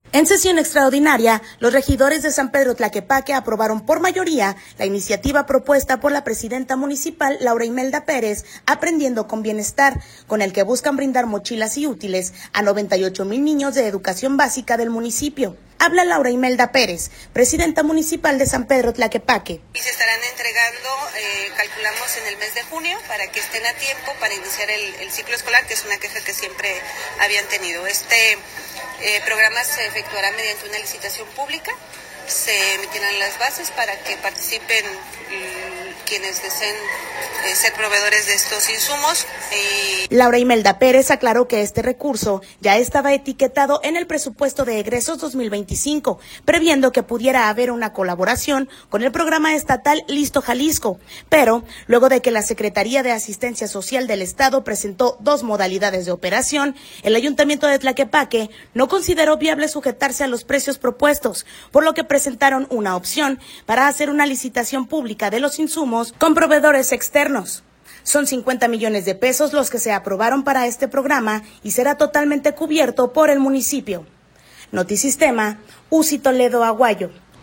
Habla Laura Imelda Pérez, presidenta municipal de San Pedro Tlaquepaque.